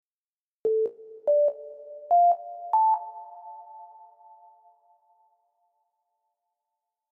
Guten Tag, ich würde gerne wissen welche Art eines Synths das ist oder ob jemand genau den Namen weiß.